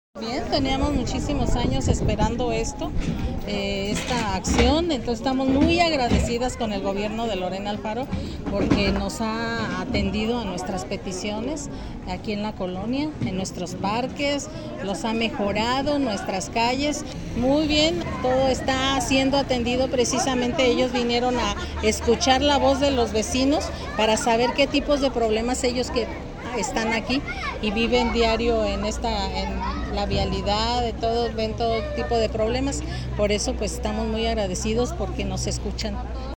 habiotante de la colonia